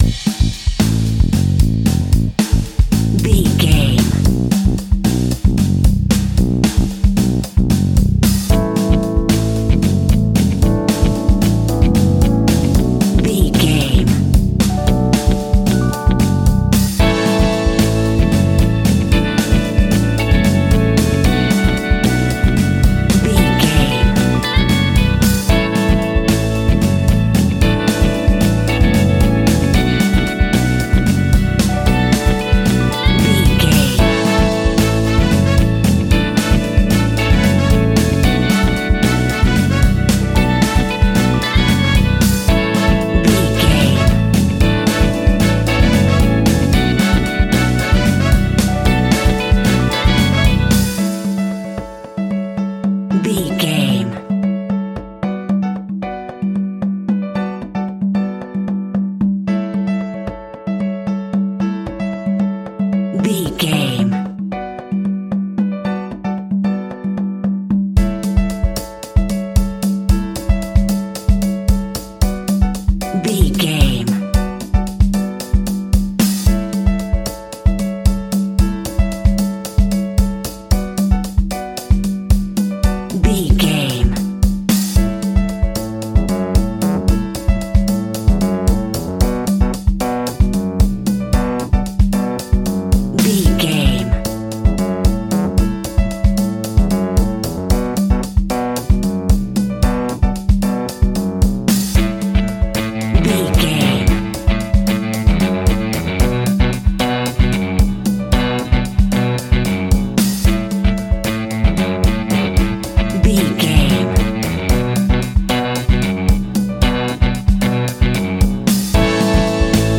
Happy Upbeat Pop Music Cue.
Ionian/Major
electro pop
pop rock
synth pop
peppy
bright
bouncy
drums
bass guitar
electric guitar
keyboards
hammond organ
acoustic guitar
percussion